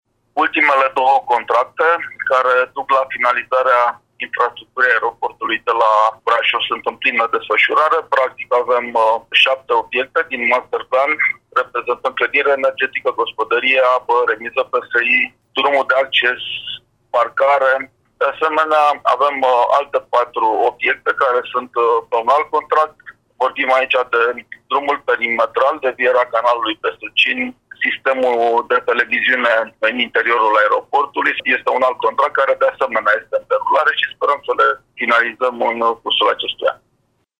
Președintele Consiliului Județean Brașov, Adrian Veștea: